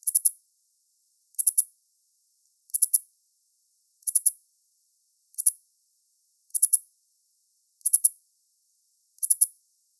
This Page is Under Construction sluggish shieldback Aglaothorax segnis Rehn and Hebard 1920 map 10 s of calling song and waveform. Lincoln County, Nevada; 24.0°C. R88-159.